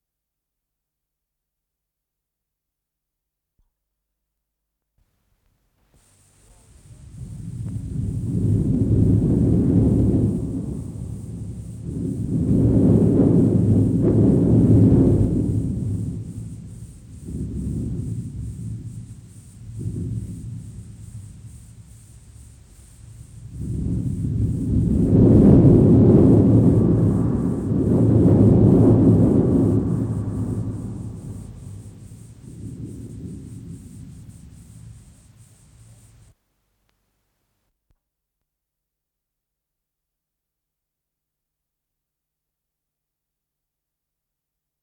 с профессиональной магнитной ленты
Скорость ленты38 см/с
Тип лентыШХЗ Тип 6